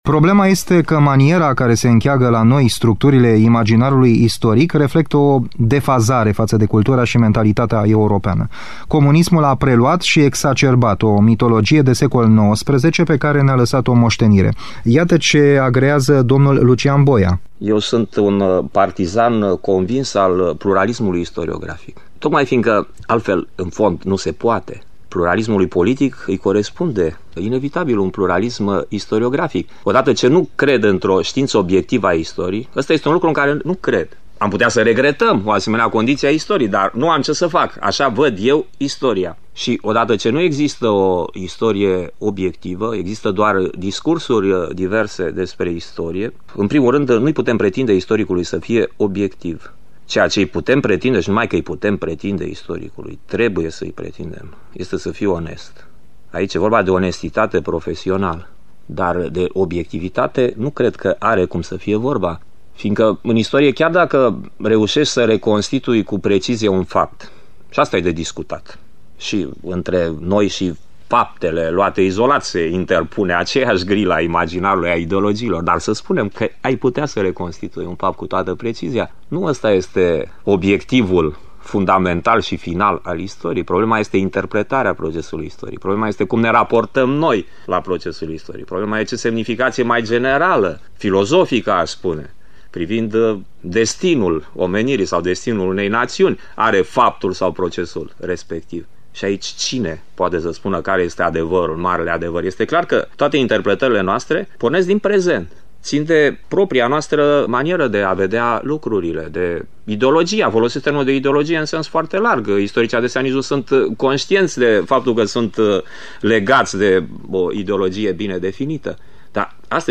în dialog cu Lucian Boia